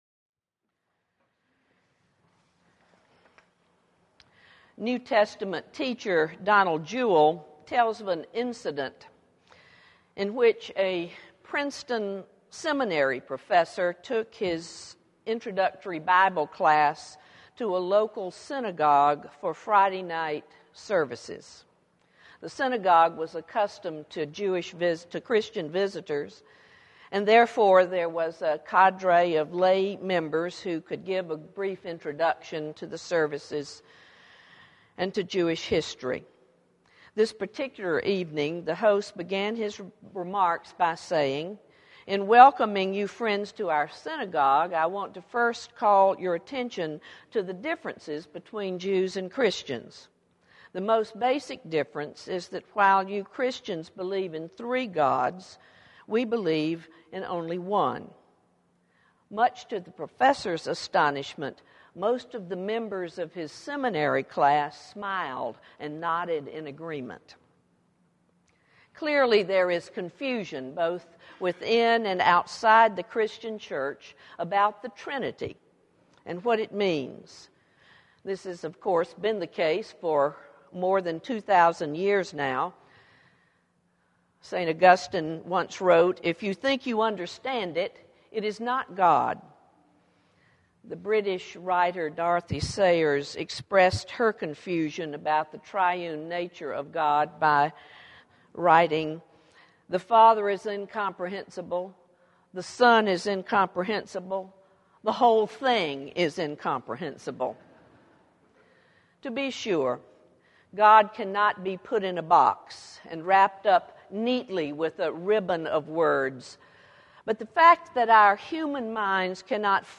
sermon_may18.mp3